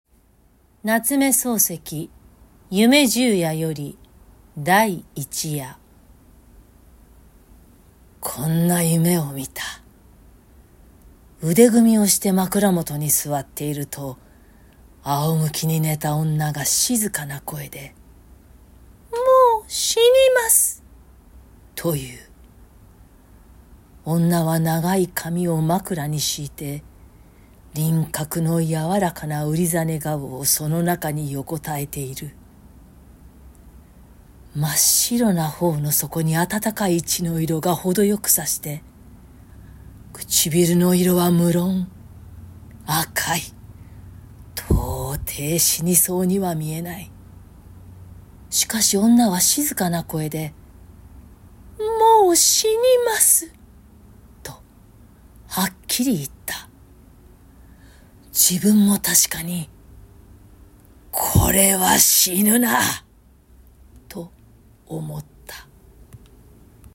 朗読音源サンプル　🔽